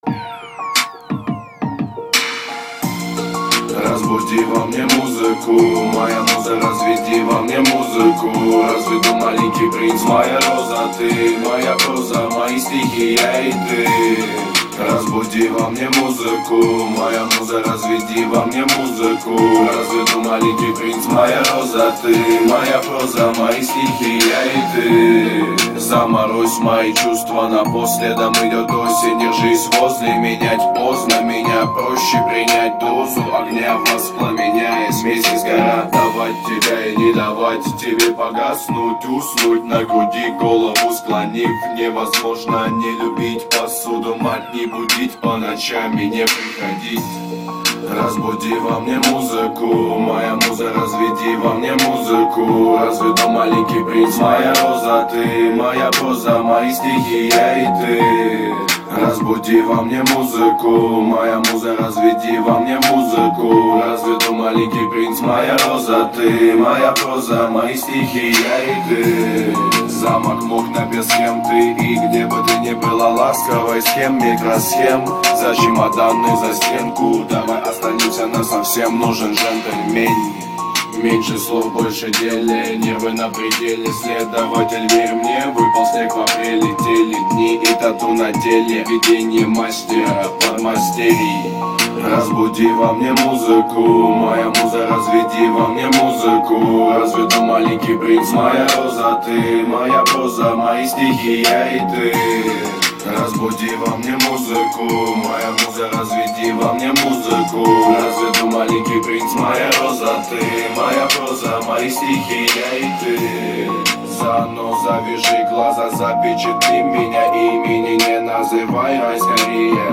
Категория: Рэп, хип - хоп